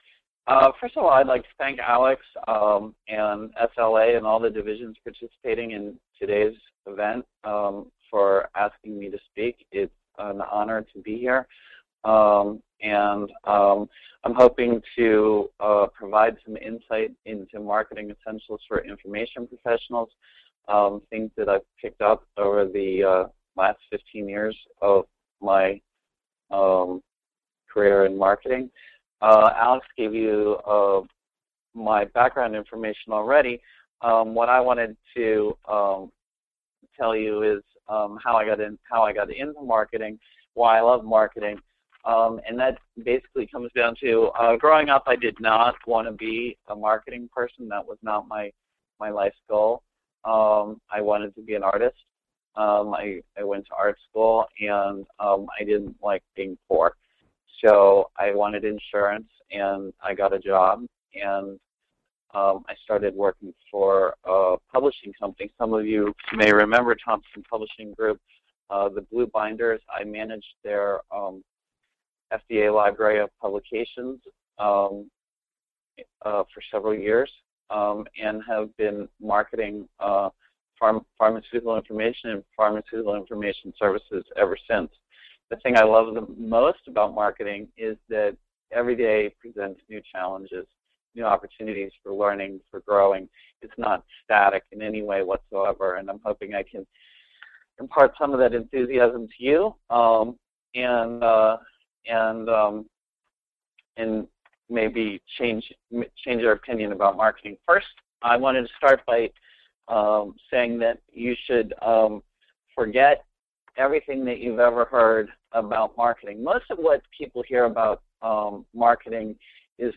Winter Virtual Conference 2012: a one-day prof ed smorgasbord!